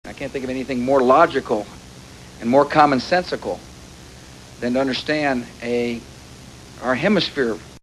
Bush defends his meetings with the leaders of Canada and Mexico in favor of European allies by uttering this beauty: